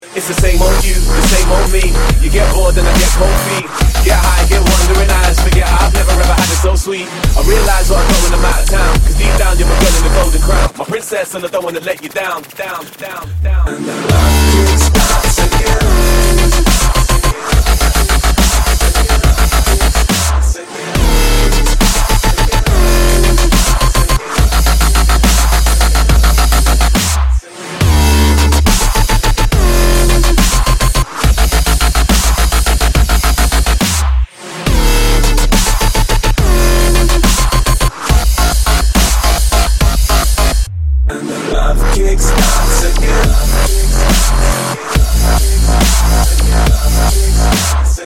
жесткие
Bass